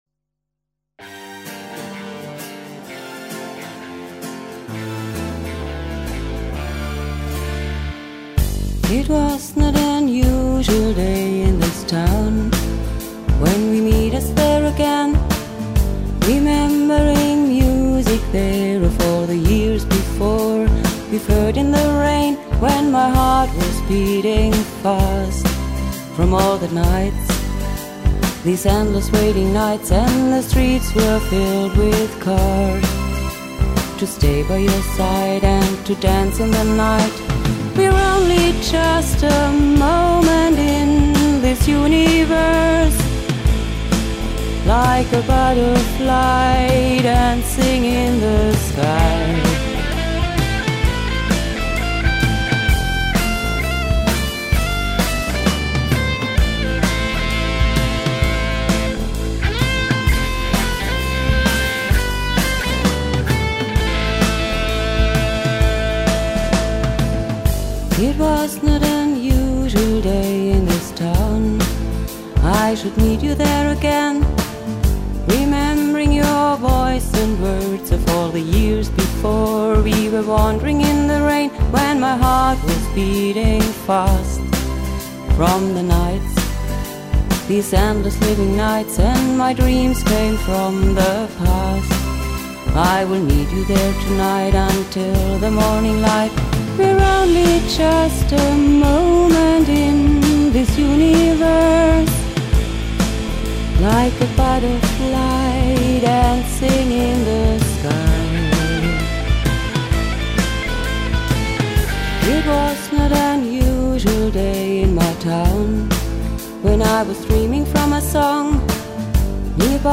After I had spend so much time with his music, now getting more and more songs of him on CDs, which he had created during the years when I could not find him in person, it happened, that I began to sit down with my simple concert-guitar and words were comming into my mind, which gave a song.